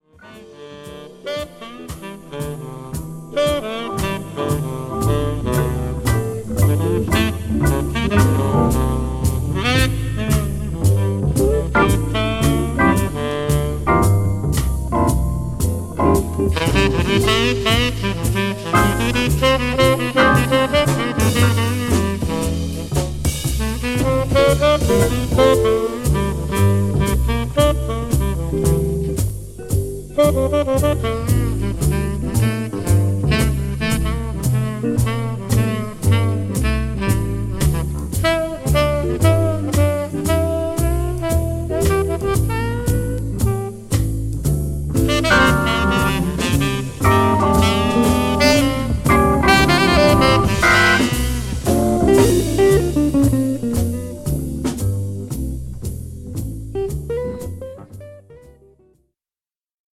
ブルージーな曲からラテン・テイスト、ファンキー、ムーディーまで、オルガン・ジャズの旨みが堪能できる名作です。